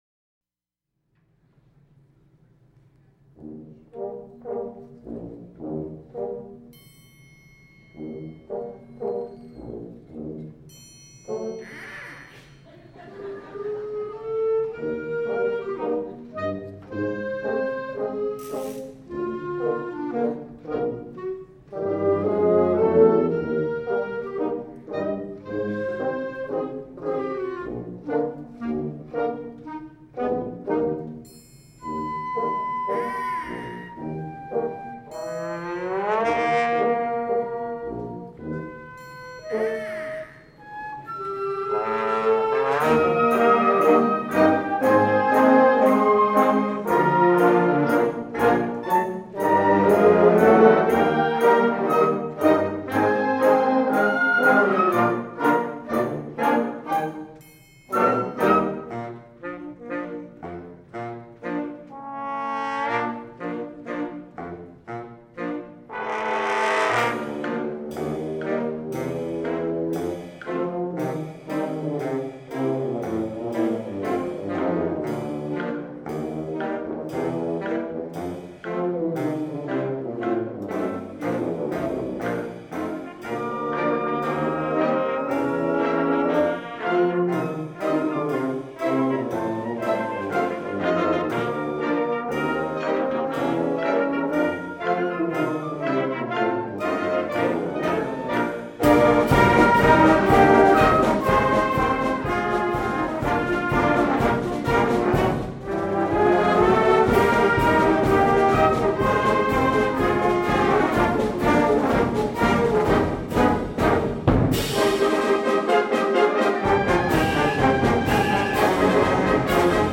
観衆（子供も大人も）が喜ぶカラフルなマーチ。ソロへのチャレンジも含まれたリズミックで巧みなユーモアのある作品。
編成：吹奏楽
Snare&BassDrum
Ratchet
BirdPipe
Police Whistle